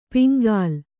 ♪ piŋgāl